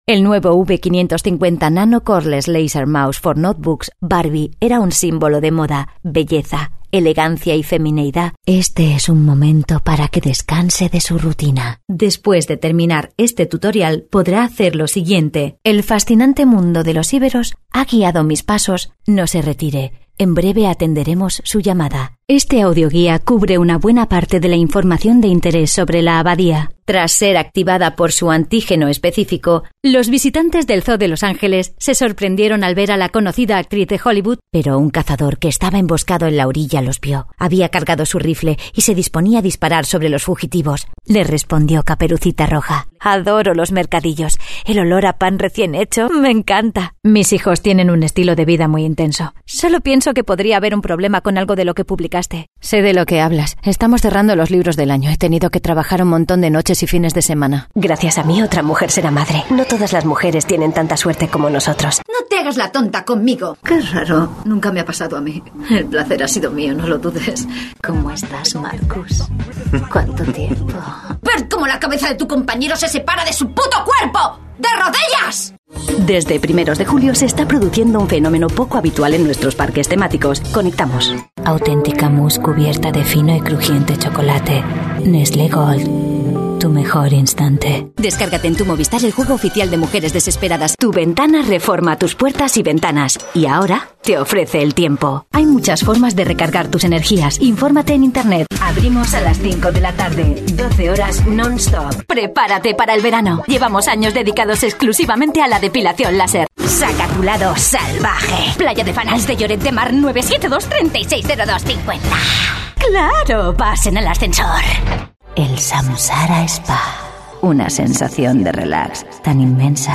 Actriz de doblaje y cantante con registro infantil, adolescente, adulto e incluso abuelitas.
Sprechprobe: Werbung (Muttersprache):
My voice is warm and clear for narrations, fun and young for commercials, professional and smooth for presentations.